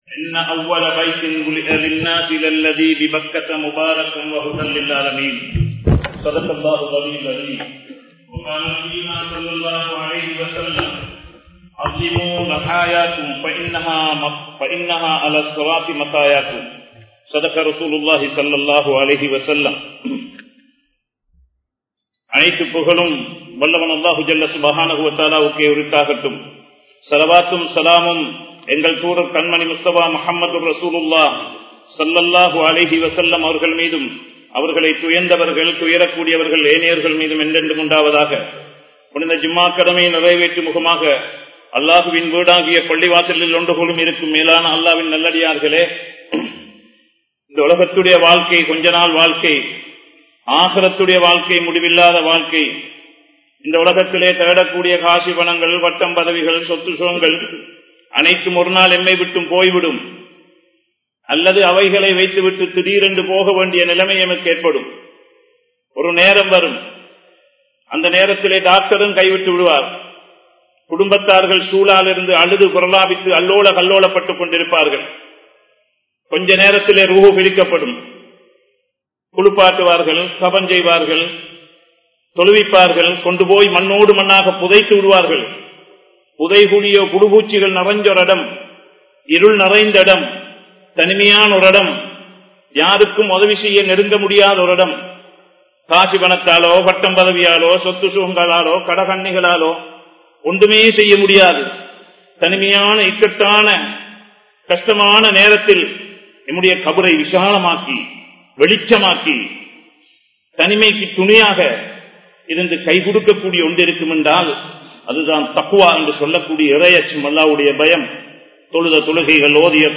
Hajjum Kurbanum (ஹஜ்ஜூம் குர்பானும்) | Audio Bayans | All Ceylon Muslim Youth Community | Addalaichenai
Colombo 11, Samman Kottu Jumua Masjith (Red Masjith)